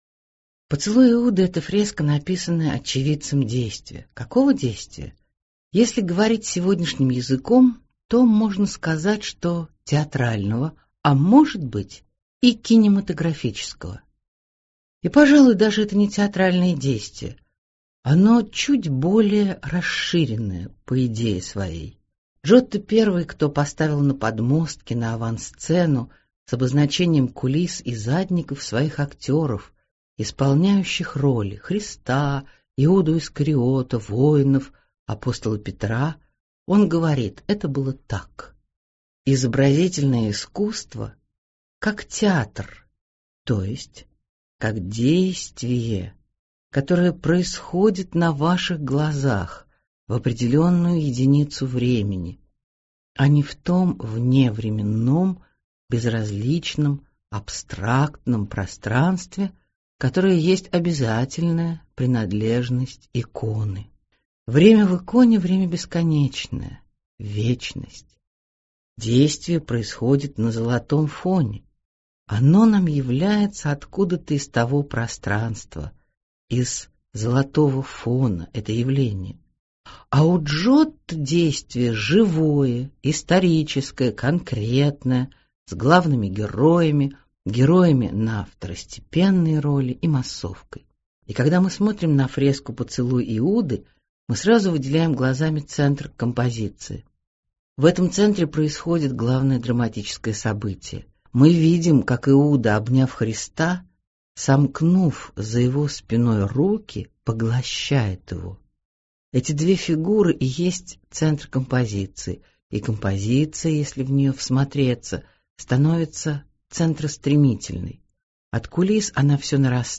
Аудиокнига Мост через бездну. В пространстве христианской культуры | Библиотека аудиокниг